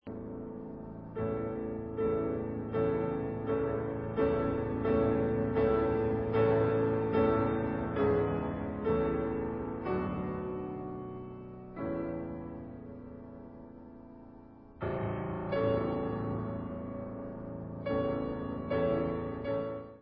sledovat novinky v kategorii Vážná hudba